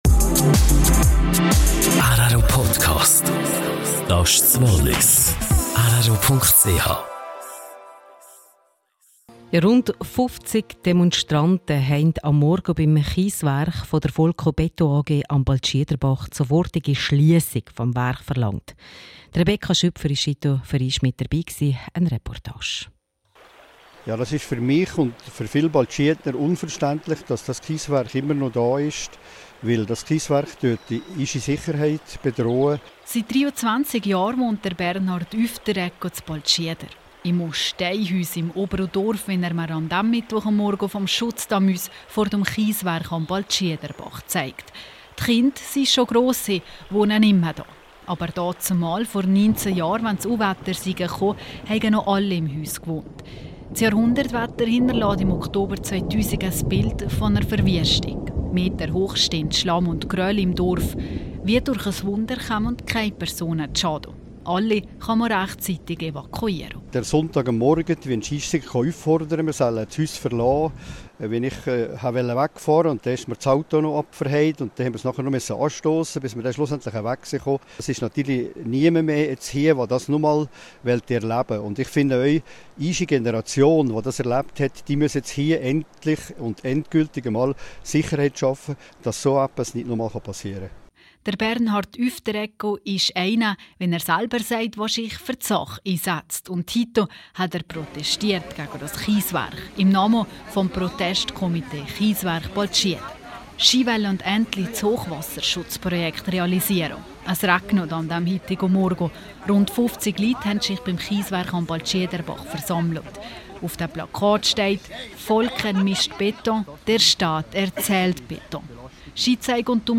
Protestaktion gegen Kieswerk in Baltschieder - eine Reportage.